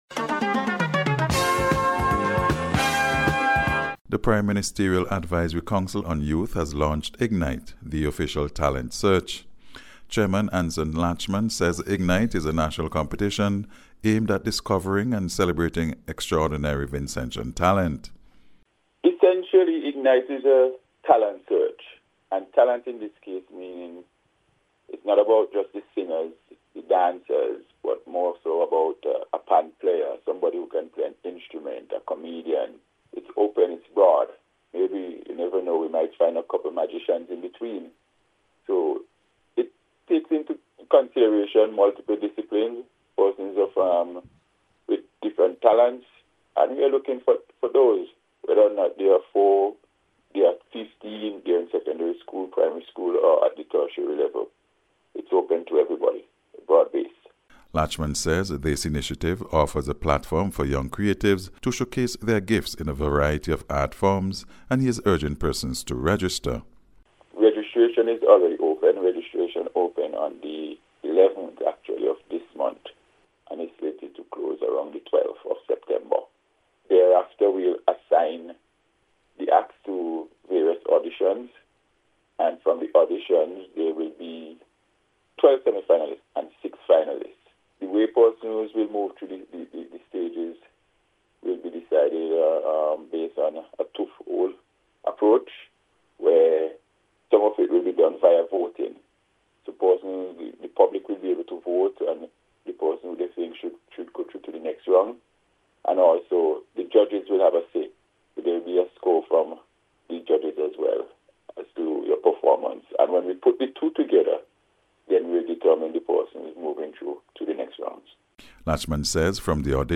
IGNITE-TALENT-SEARCH-REPORT.mp3